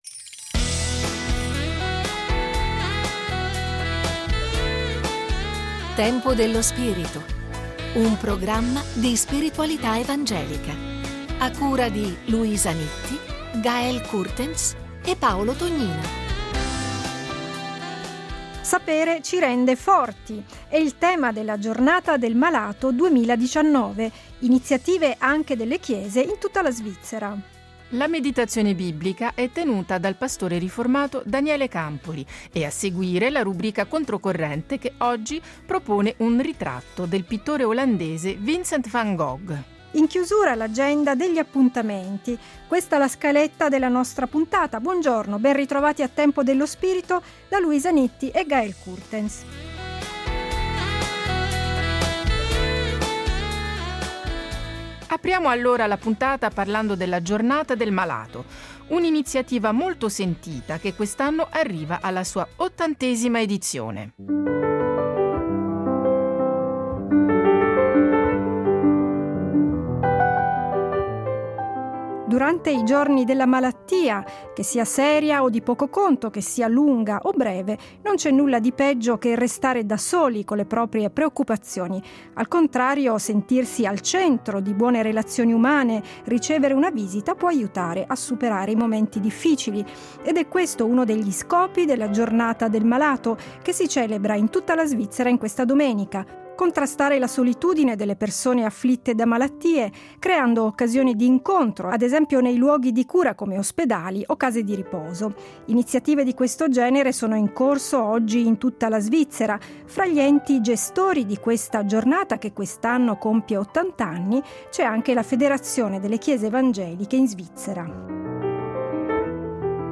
un’intervista